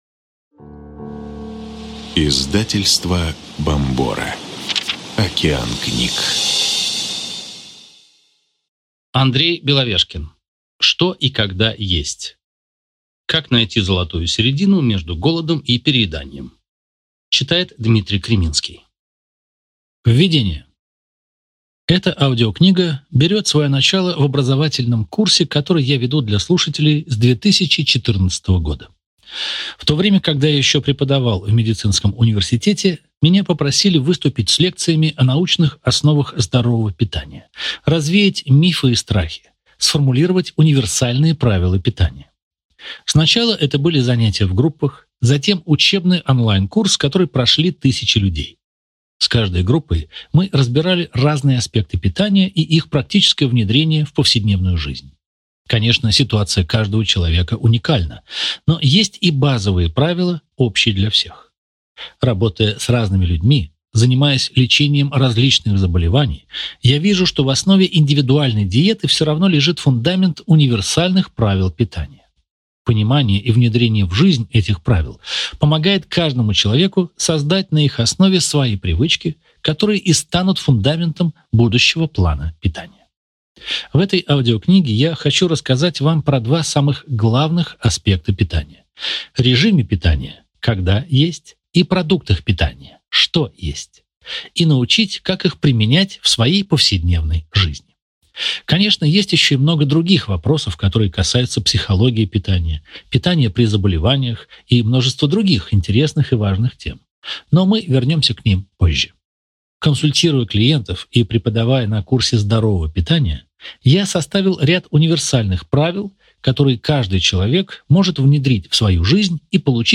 Аудиокнига Что и когда есть. Как найти золотую середину между голодом и перееданием | Библиотека аудиокниг